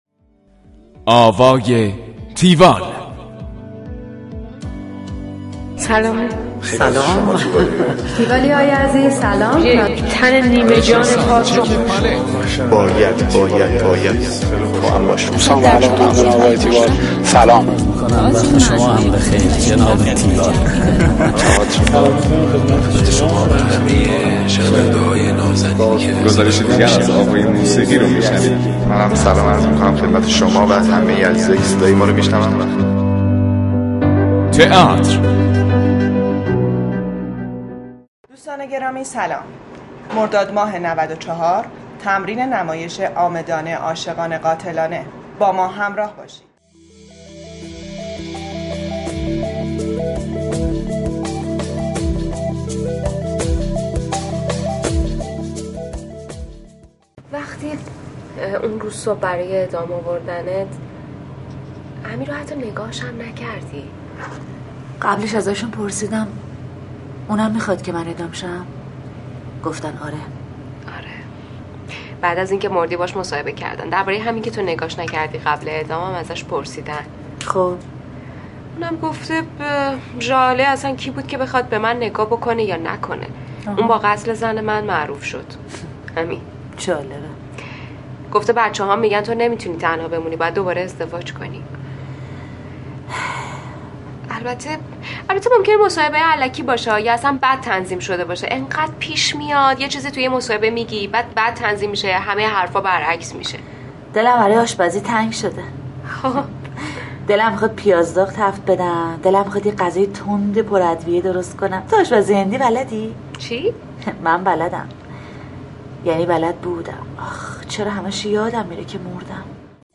گزارش آوای تیوال از نمایش عامدانه، عاشقانه، قاتلانه
همراه با بخش هایی از تمرین